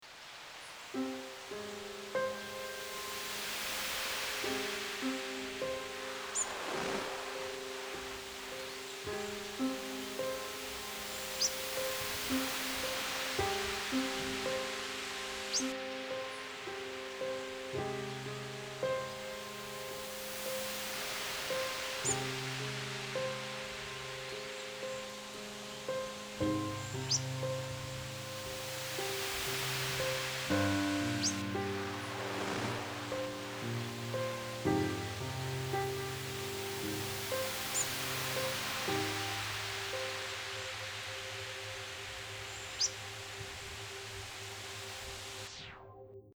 My DIY, home made analog modular synthesizer
Here are some of the sounds produced by this thing. In a few of these samples I incorporated minimal composition so the less musically imaginative can get a sense of how this might be used.
beach.mp3